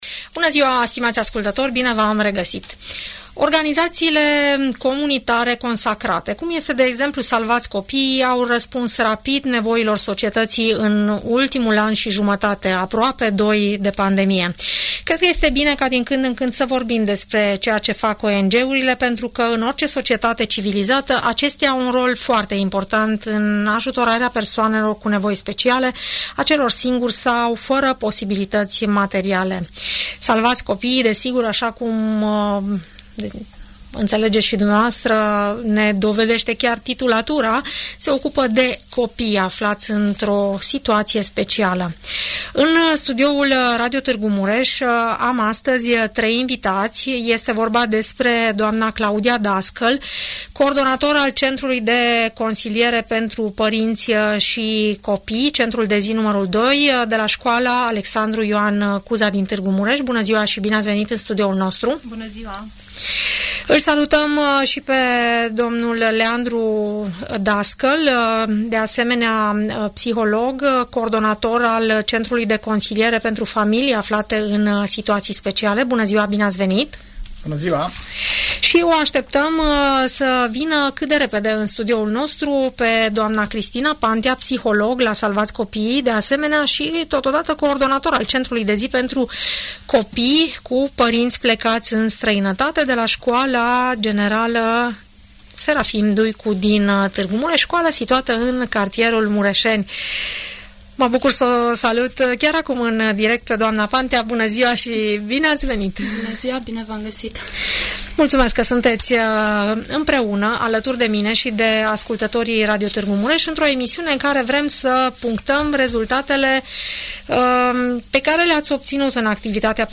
ONG-urile sprijină micuții cu nevoi speciale! Reprezentanții „Salvați copiii”, vorbesc la Radio Tg. Mureș despre acțiunile derulate constant în ultimii doi ani și despre planurile viitoare.